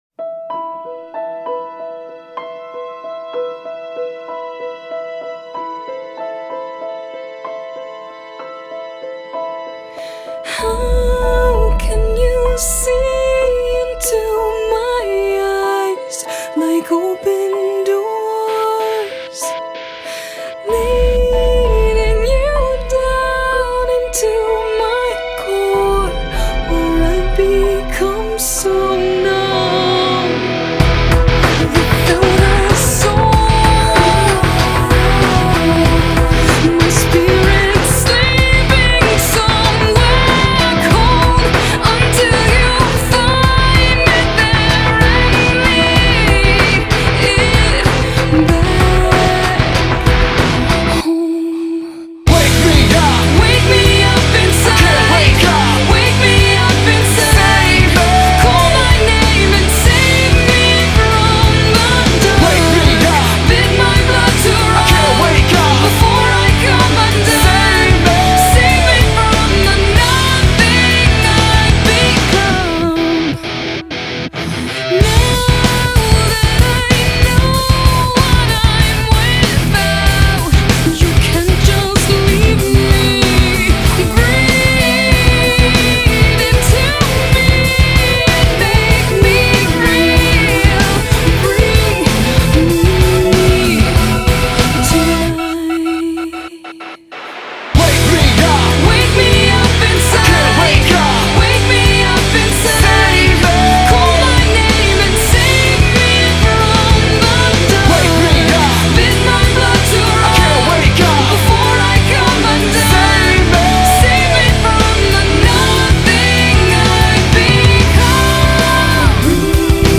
راک